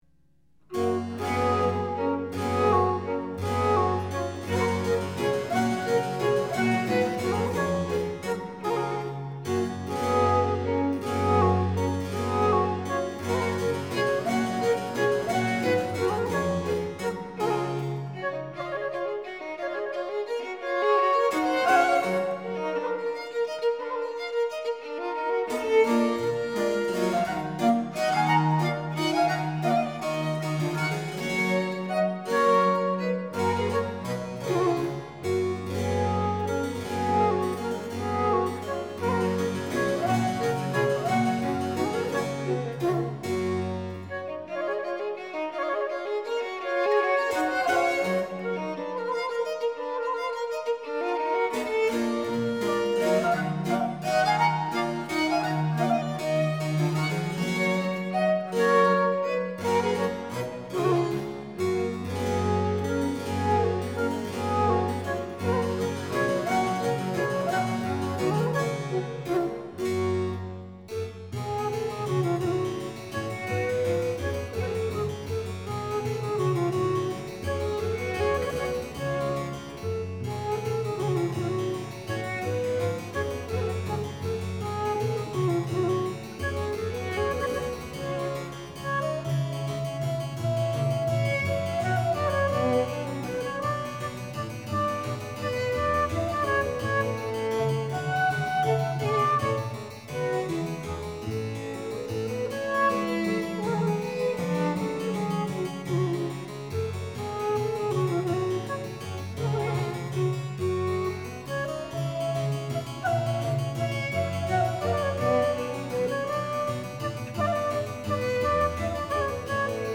Flute
Harpsichord
Viola da gamba
Cello